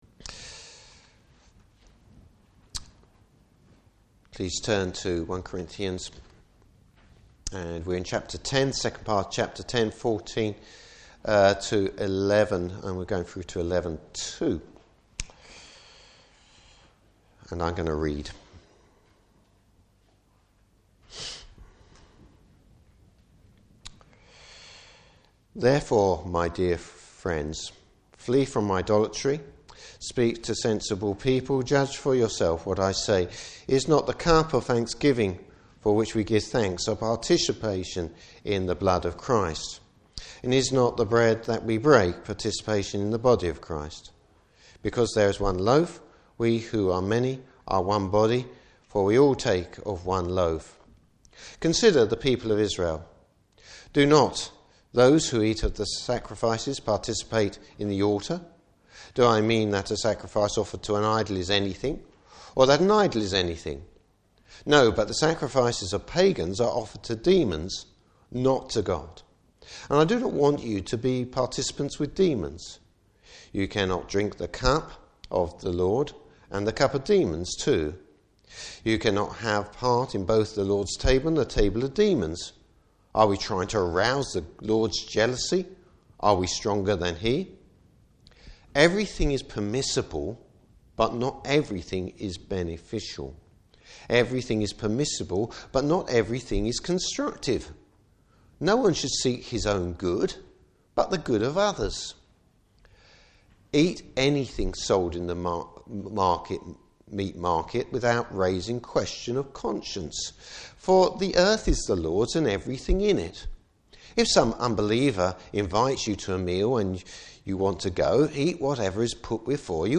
Service Type: Morning Service The believer’s freedom in relation to fellowship.